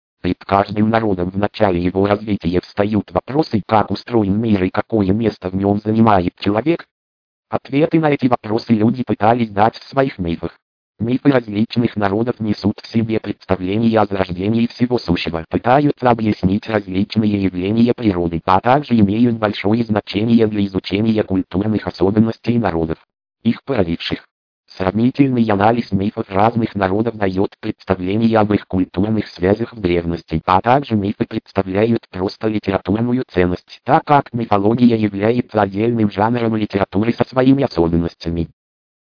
L&H tts 3000 Russian (Lernout & Hauspie) - это русскоязычный синтезатор стандарта SAPI4.
Данный синтезатор имеет определённую картавость в произношении, однако к его плюсам можно отнести довольно приличное произношение не только русского, но и английского текста, которое позволяет использовать его и для чтения англоязычных документов.